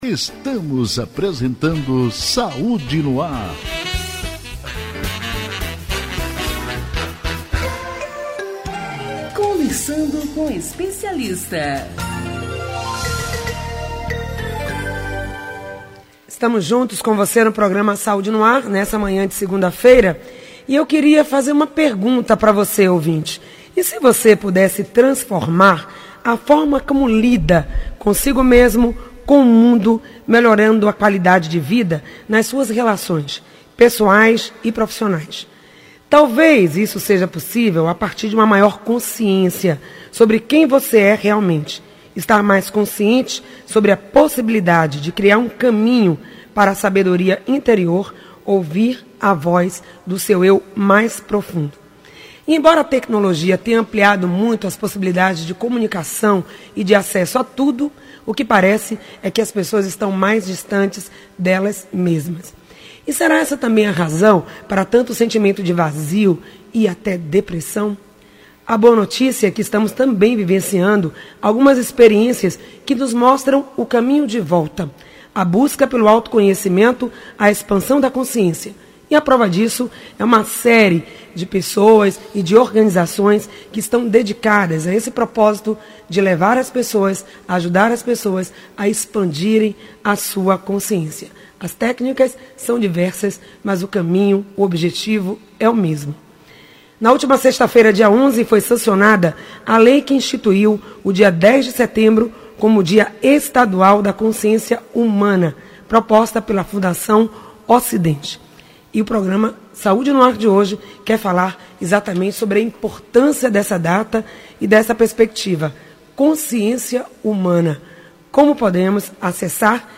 Ouça a entrevista na íntegra ou assista ao vídeo no Facebook